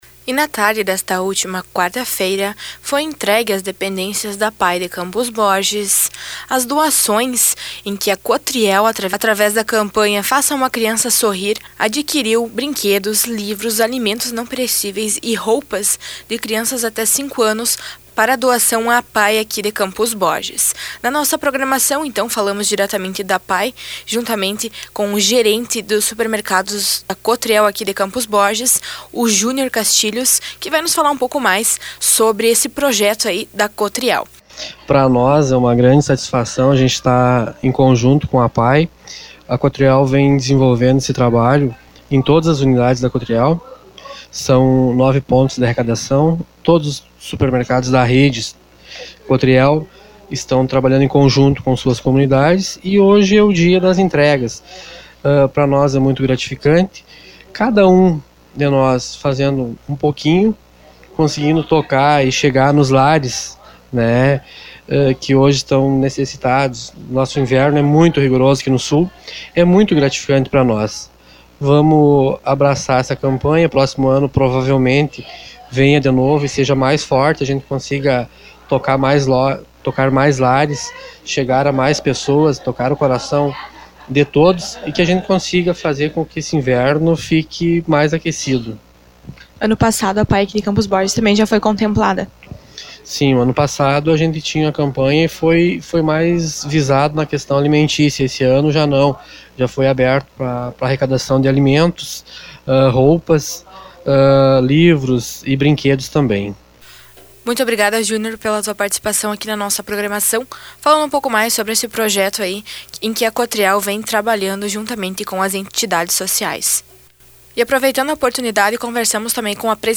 aqui a entrevista da repórter